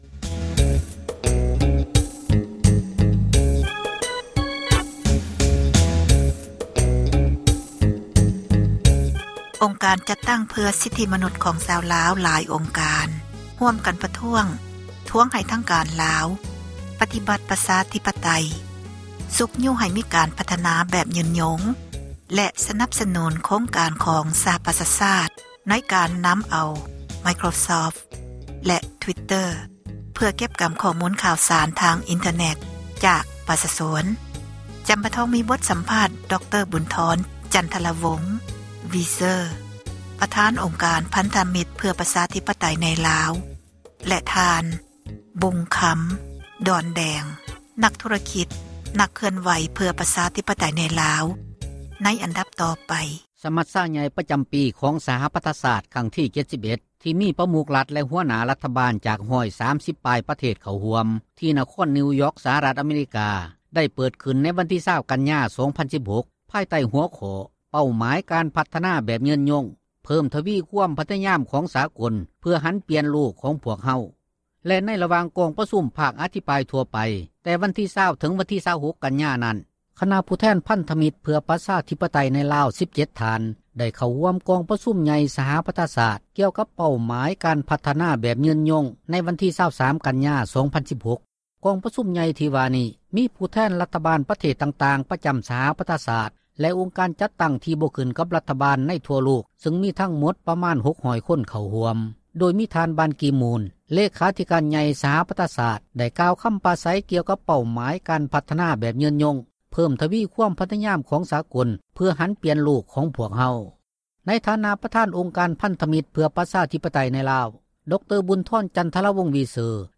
ສັມພາດນັກເຄື່່ອນໄຫວ ການເມືອງ